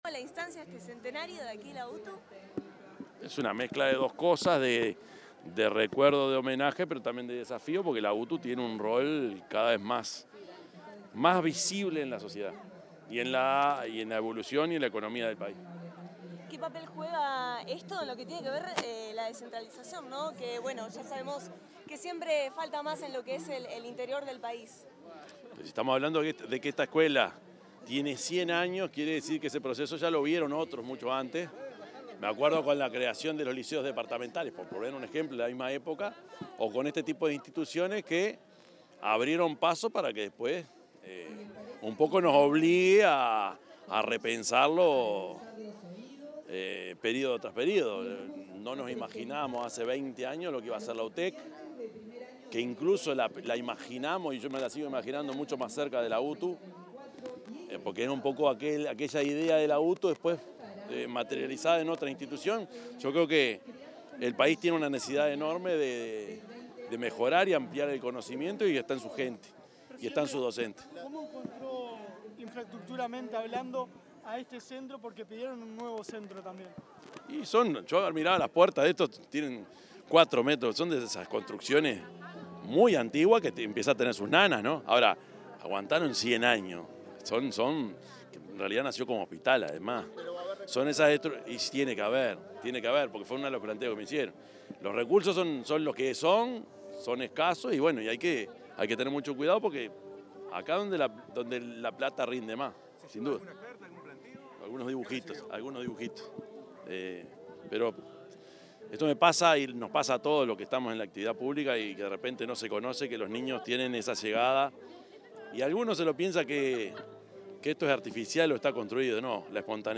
Declaraciones del presidente Yamandú Orsi a la prensa
Declaraciones del presidente Yamandú Orsi a la prensa 23/05/2025 Compartir Facebook X Copiar enlace WhatsApp LinkedIn El presidente de la República, profesor Yamandú Orsi, brindó declaraciones a la prensa tras participar en el acto por el centenario de la creación de la Escuela Técnica de San Carlos, departamento de Maldonado.